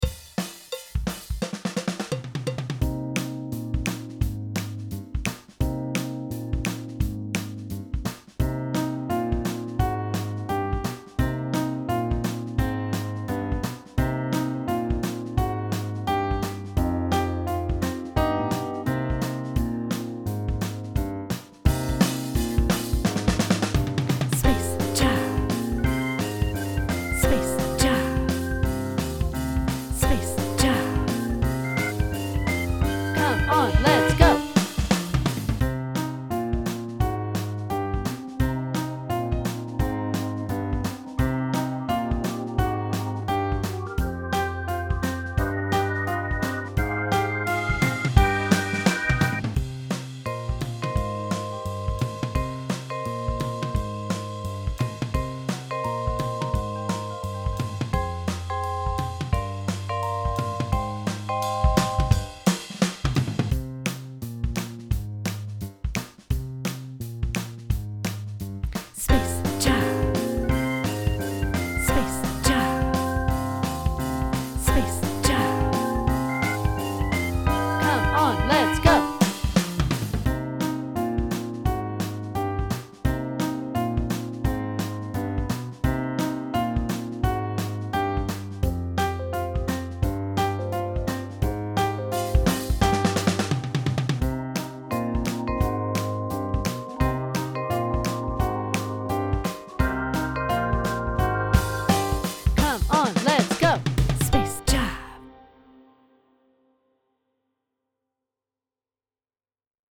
Key: C Blues Scales
Time Signature: 4/4 (BPM ≈ 146–178)